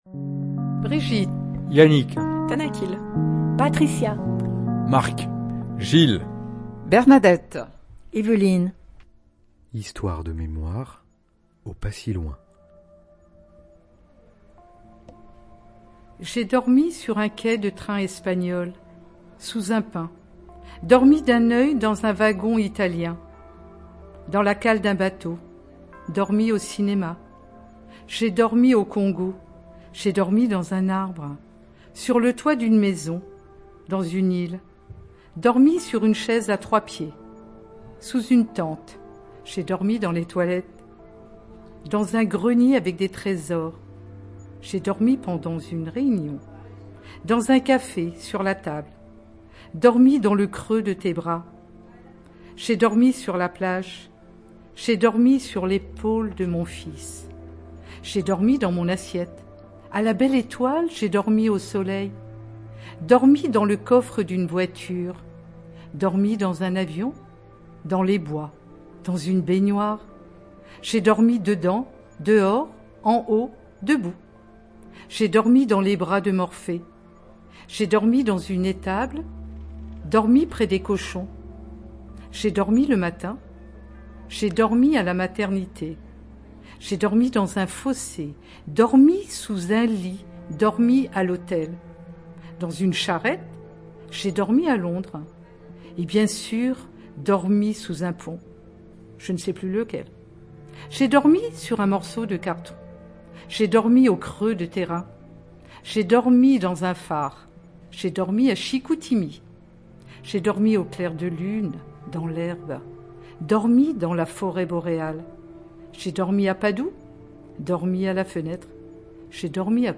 Ateliers senior - Écriture et collecte de récits
Son but est de créer un podcast / feuilleton numérique mis en forme par un créateur sonore, disponible sur un site internet, rassemblant les récits des participant.e.s. et leur offrant une trace sensible des moments vécus au cours du projet.